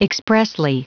Prononciation du mot expressly en anglais (fichier audio)
Prononciation du mot : expressly